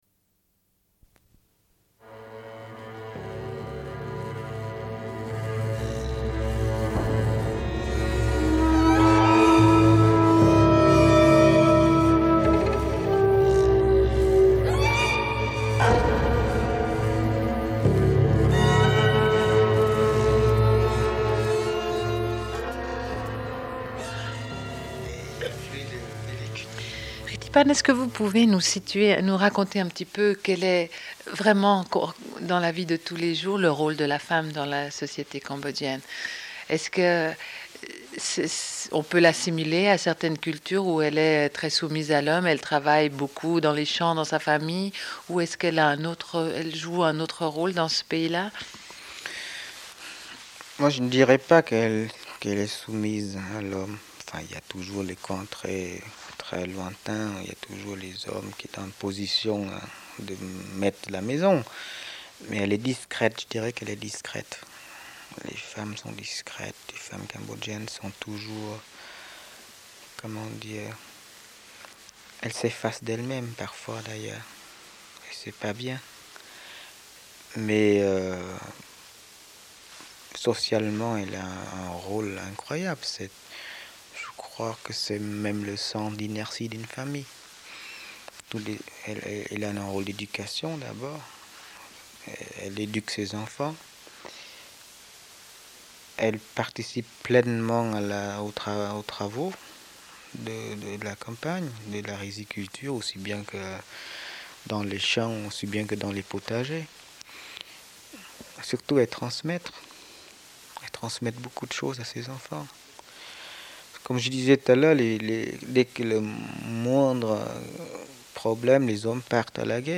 Une cassette audio, face A31:26
Sommaire de l'émission : diffusion d'un entretien avec Rithy Panh, cinéaste cambodgien, au sujet de son film Les gens de la rizière.